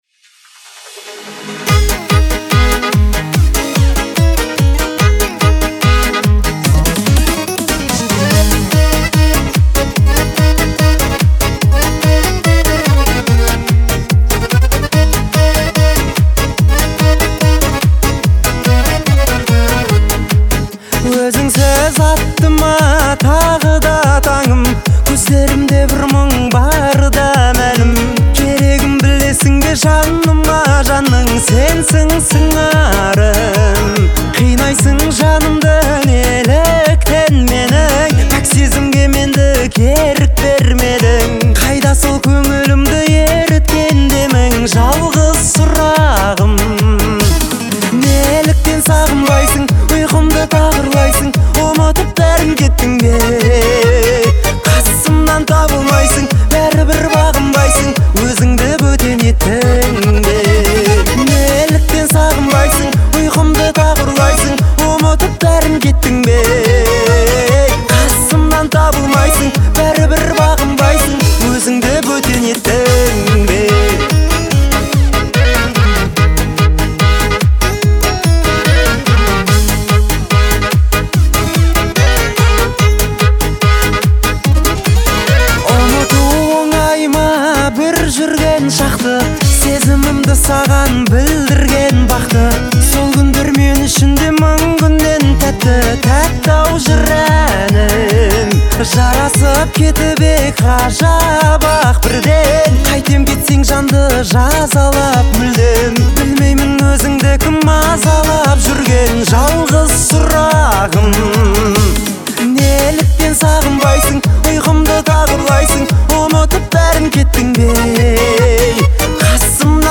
казахской поп-музыки
пронизана меланхоличным настроением